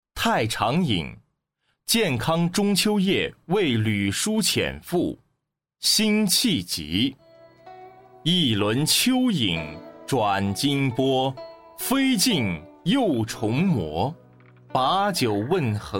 九语下第三单元课外古诗词诵读-太常引·建康中秋夜为吕叔潜赋 课文朗读（素材）